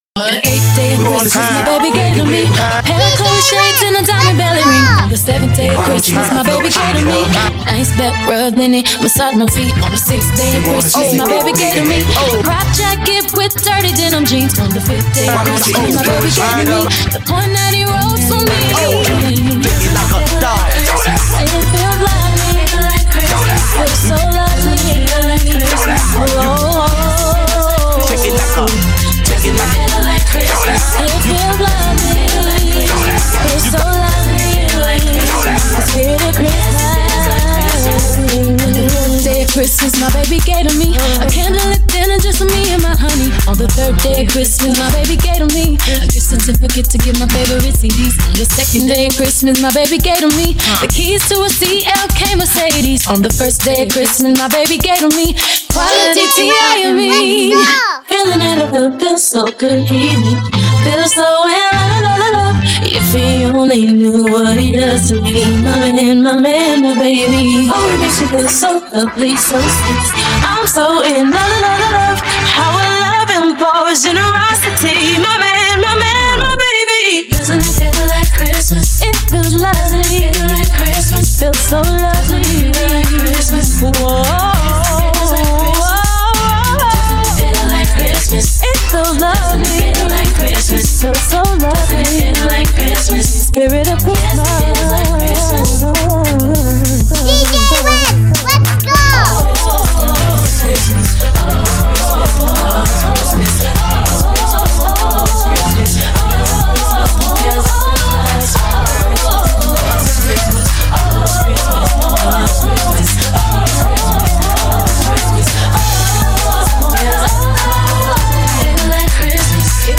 HipHop
RnB